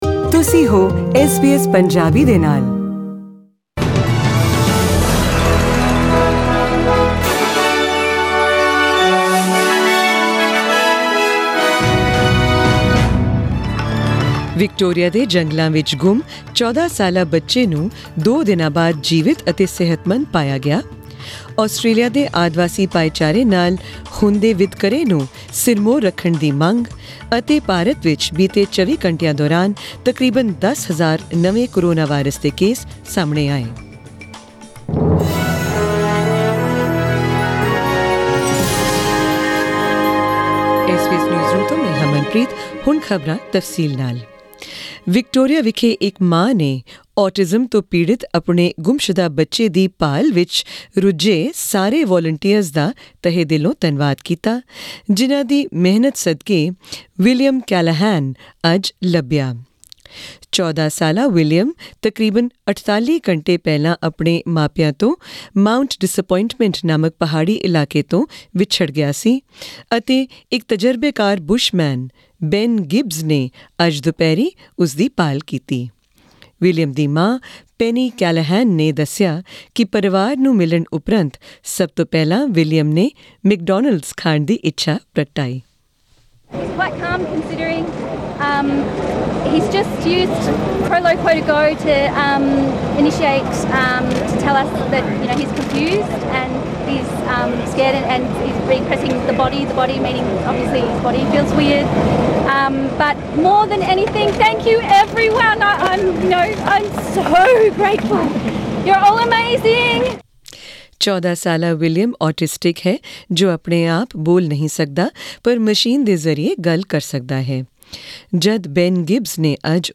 Also in tonight's bulletin, hear more about